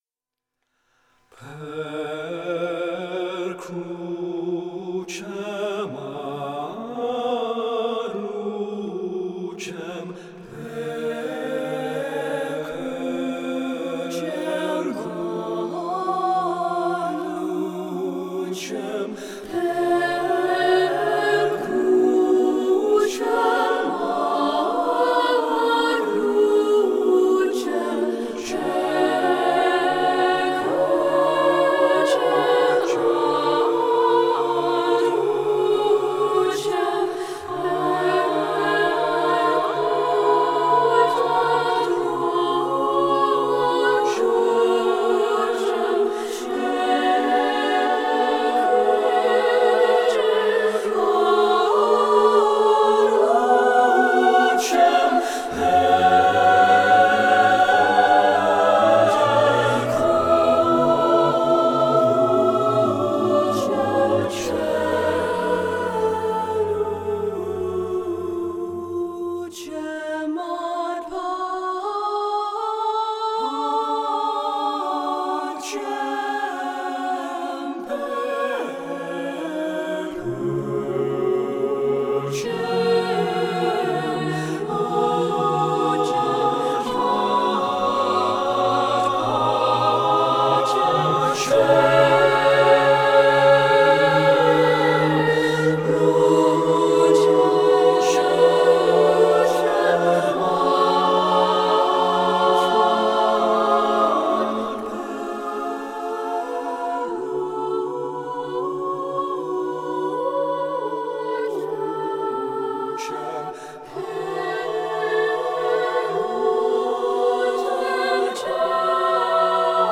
I had originally conceived a very modern arrangement, a slow evolution of minor and sometimes dissonant chords rising up and eventually resolving into a triumphant major chord, but once the phrase took on a melodic form I knew it had to be something more of a Bach-like fugue.